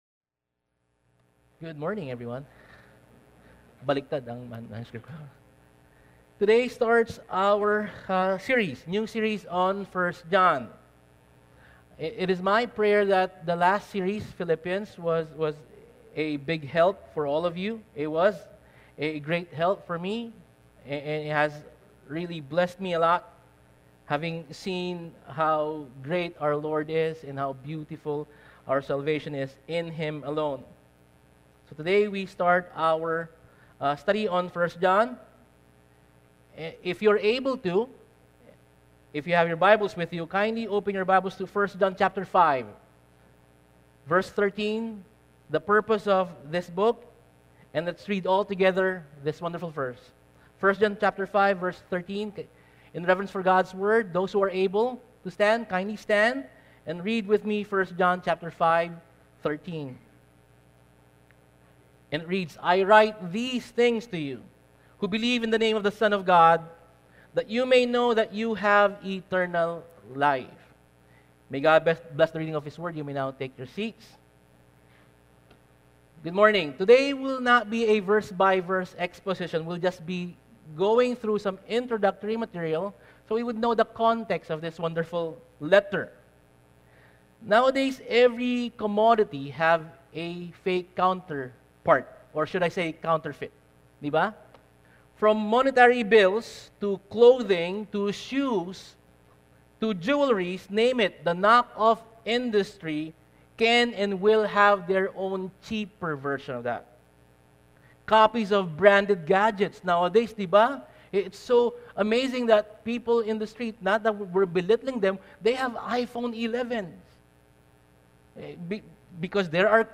Service: English